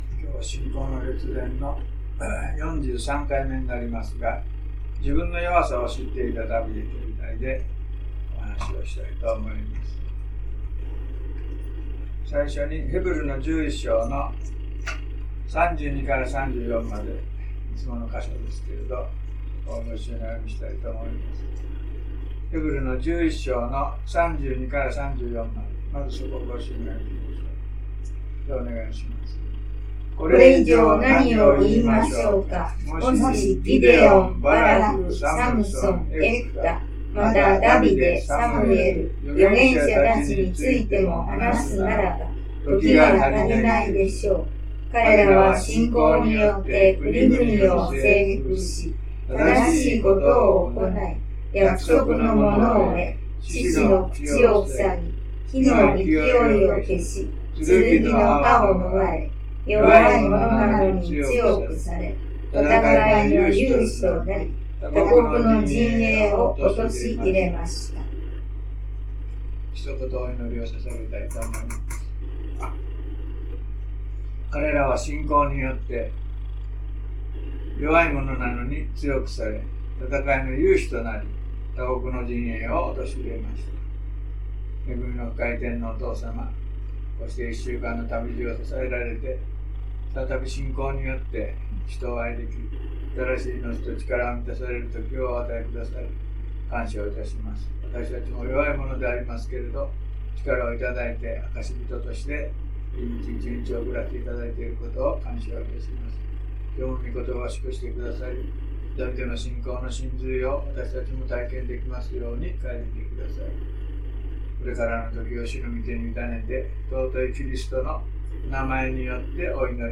2017年6月11日 (日) 午前10時半 礼拝メッセージ